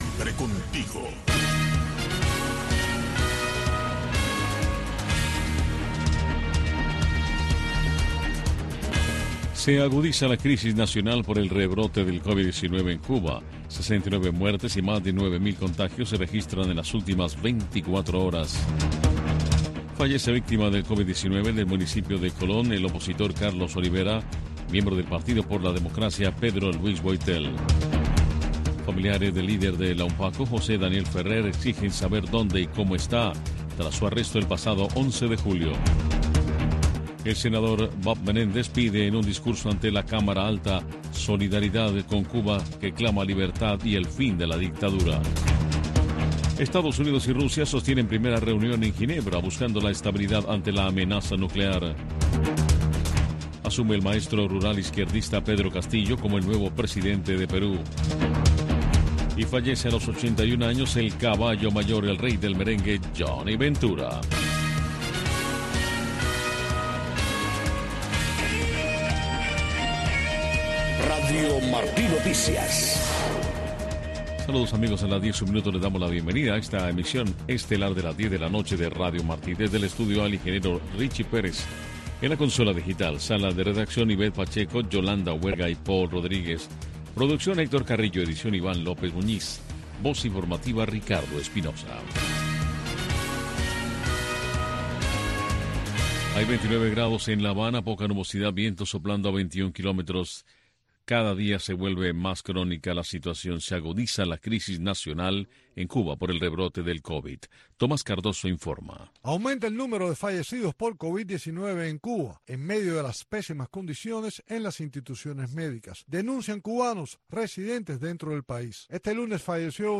Noticiero de Radio Martí 10:00 PM